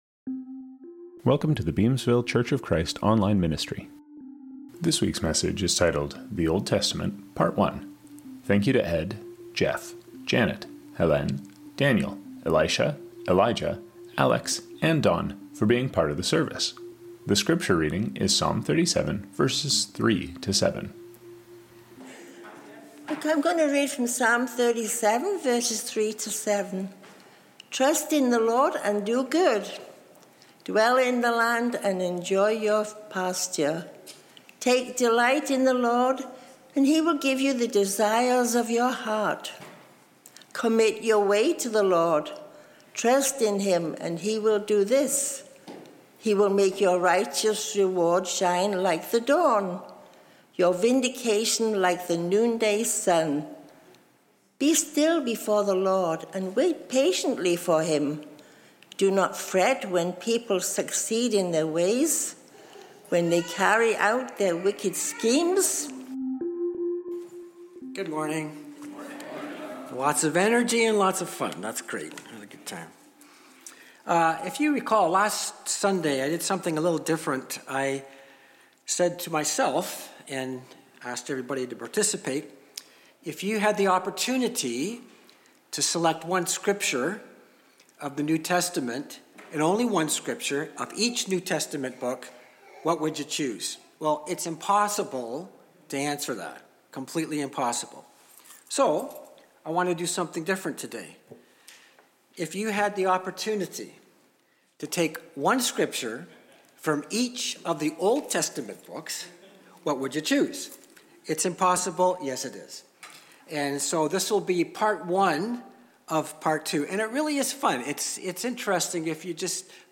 The weekly sermon from the Beamsville Church of Christ